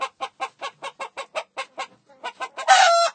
cf_hen_clucking.ogg